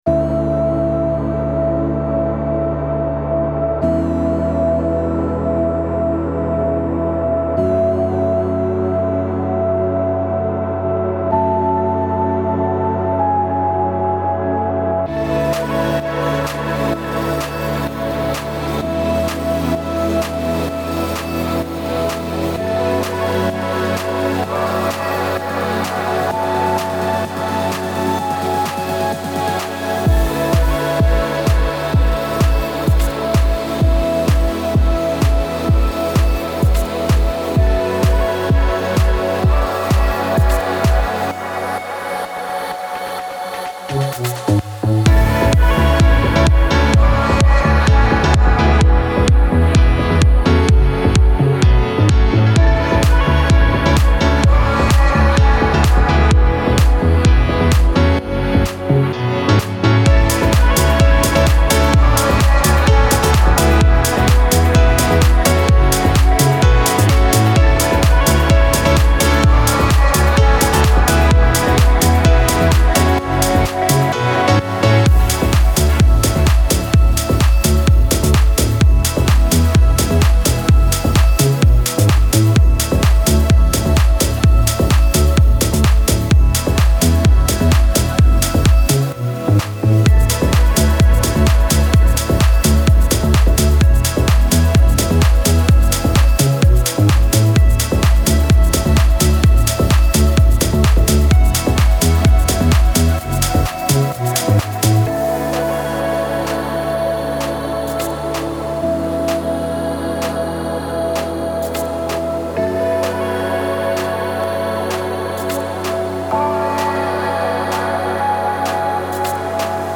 Genre : Électronique, Dance